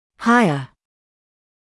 [‘haɪə][‘хайэ]более высокий; выше